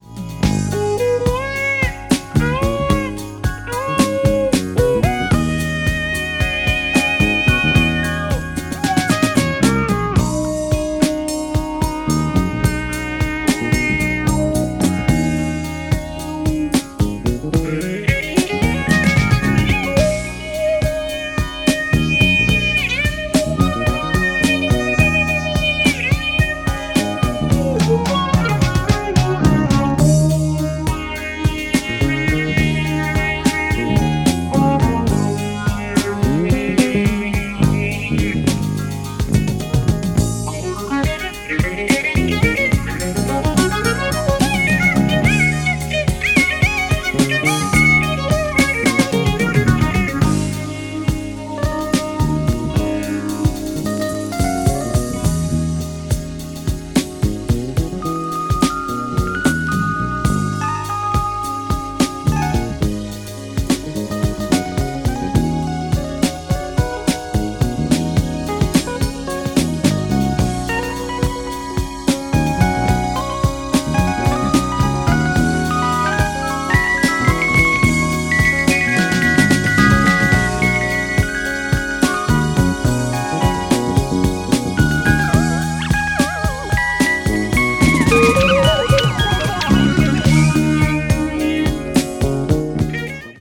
ジャンル(スタイル) JAZZ / BALEARIC / NU DISCO / EDITS